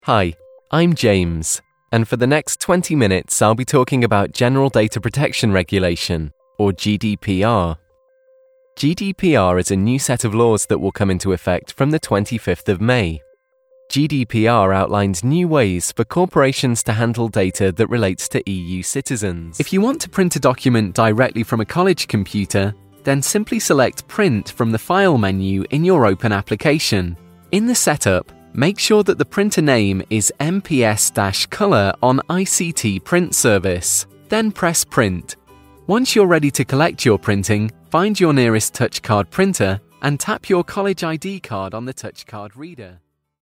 Elearning and Training Videos
These require a clear yet engaging tone of voice as the audience often listen for many hours and need to retain the information.